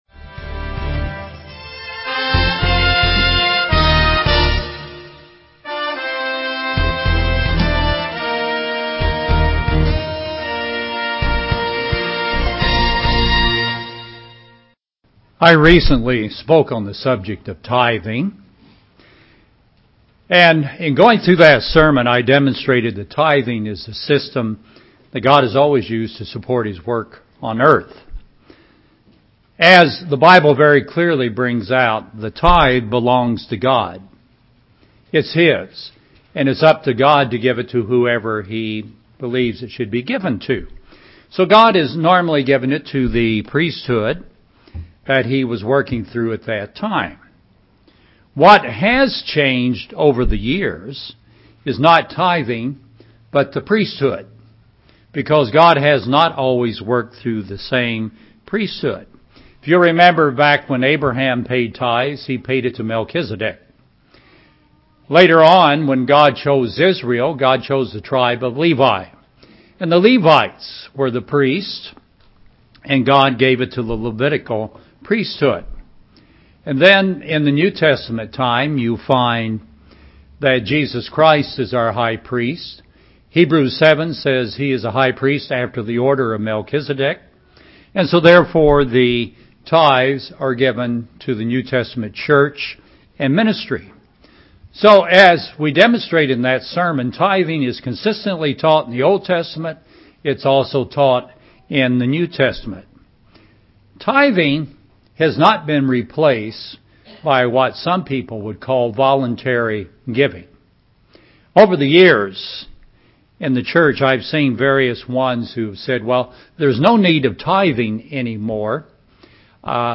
Deuteronomy 14:22-28 UCG Sermon Transcript This transcript was generated by AI and may contain errors.